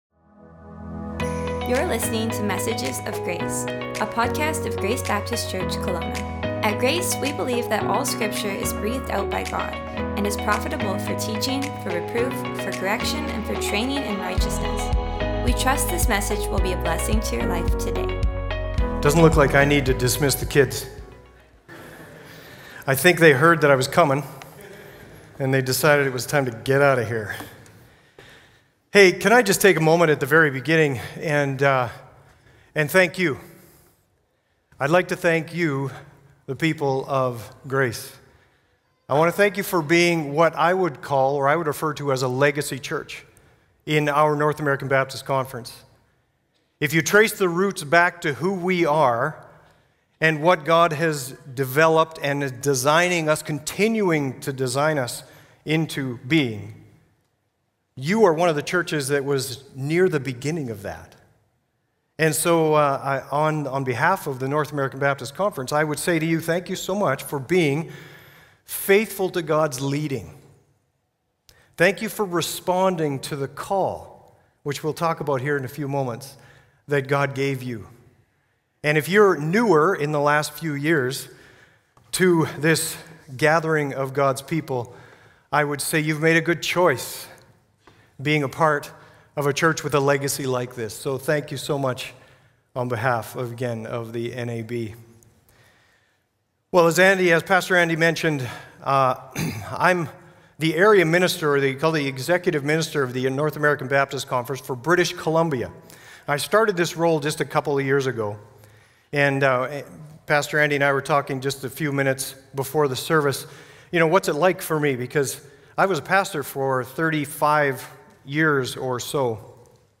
Guest Speaker
Stand Alone: Single Sermons